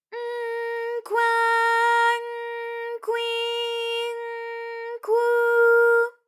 ALYS-DB-001-JPN - First Japanese UTAU vocal library of ALYS.
kw_N_kwa_N_kwi_N_kwu.wav